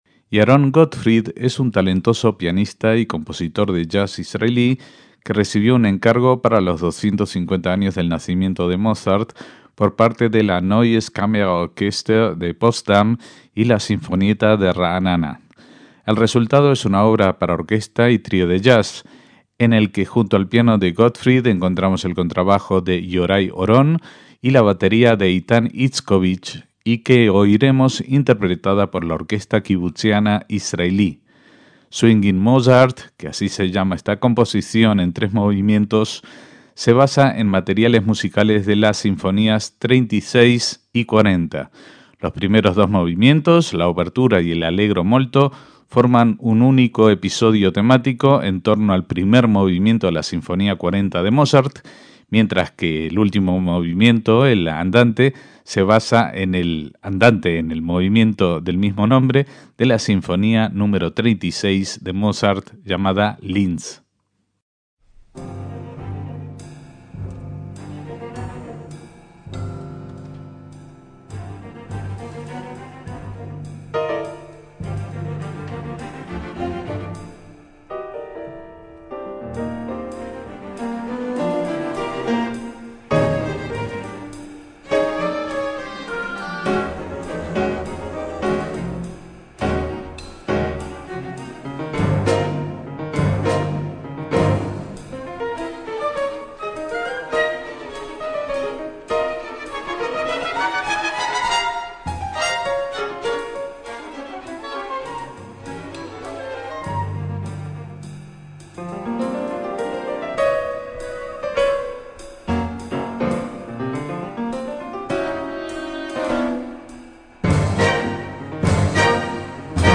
trío de jazz
orquesta sinfónica